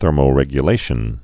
(thûrmō-rĕgyə-lāshən)